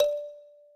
kalimba_d.ogg